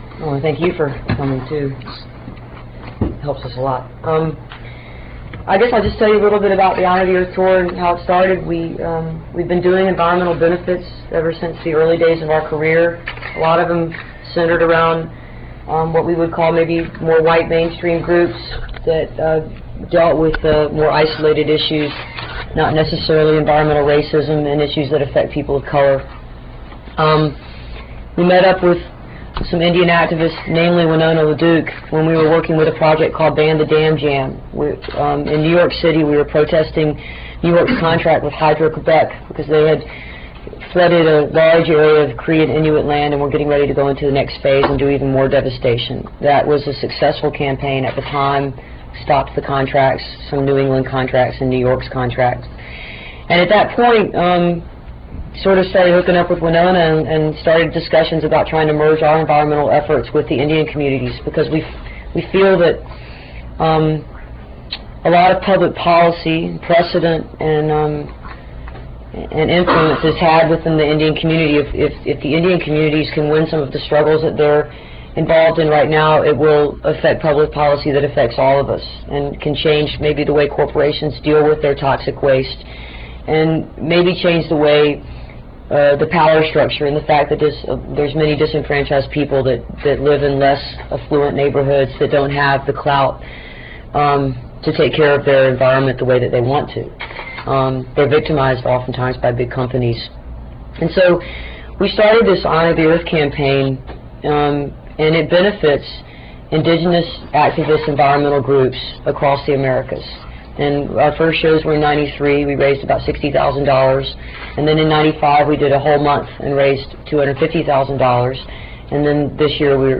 lifeblood: bootlegs: 1997-09-15: honor the earth press conference - poughkeepsie, new york
04. press conference - amy ray (2:11)